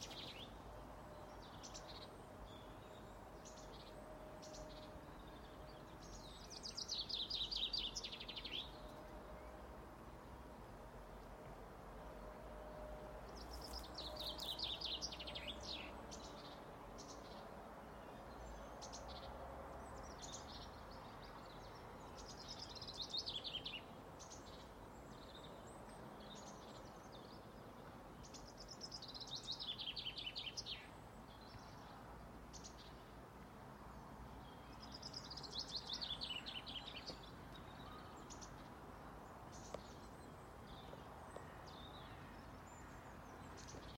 Žubīte, Fringilla coelebs
Administratīvā teritorijaValkas novads
StatussDzied ligzdošanai piemērotā biotopā (D)